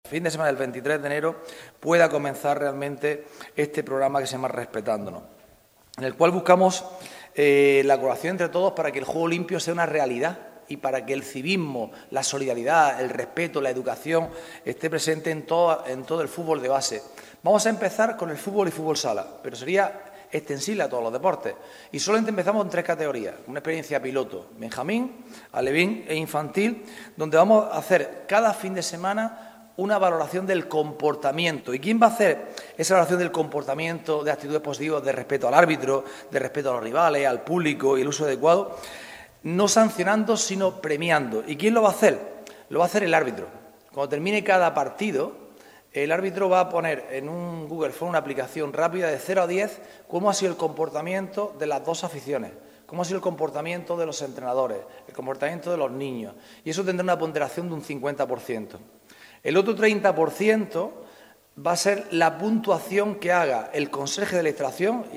ANTONIO-JESUS-CASIMIRO-CONCEJAL-CIUDAD-ACTIVA.mp3